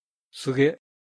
/sɯge/.